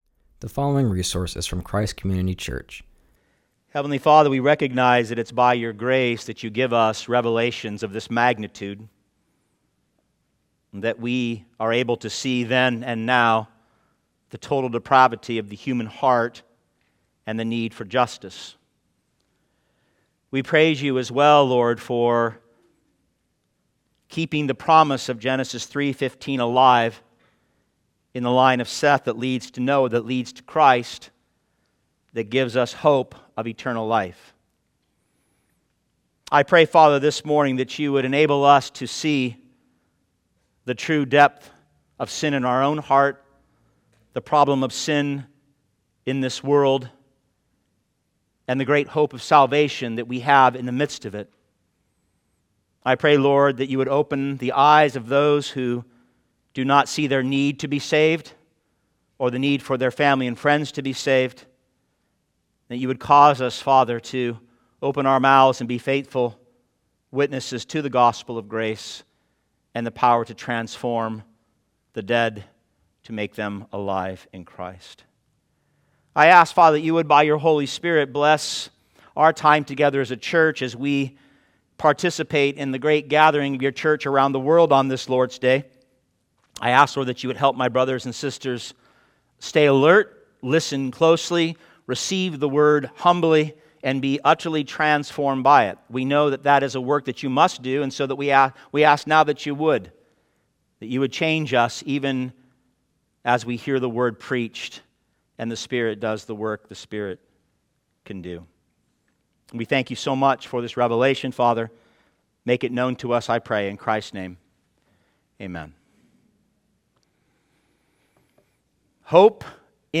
preaches from Genesis 6:1-8.